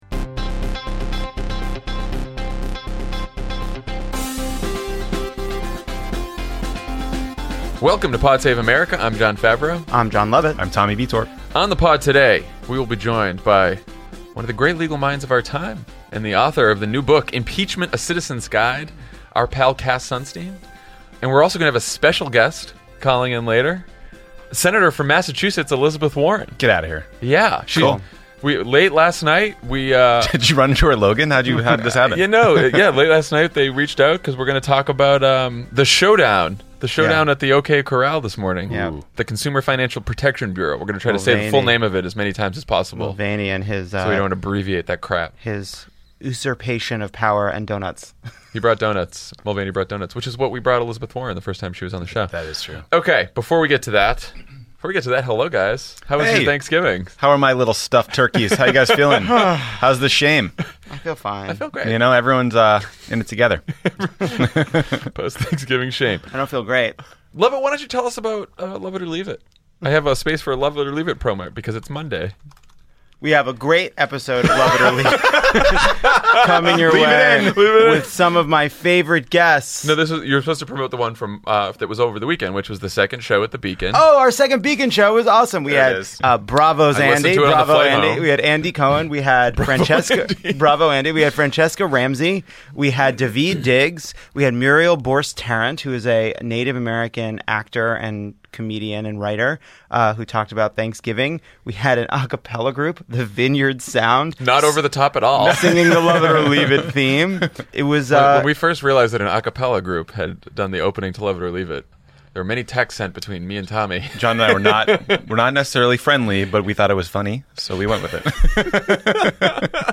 Republicans don’t yet have the votes to jam through their Donor Relief Act, Trump goes all-in for Moore, Conyers steps down from Judiciary, Tillerson guts State, and Mulvaney appoints himself consumer watchdog. Then Senator Elizabeth Warren talks to Jon, Jon, and Tommy about the Consumer Financial Protection Bureau, and Cass Sunstein joins to talk about his new book, Impeachment: A Citizen’s Guide.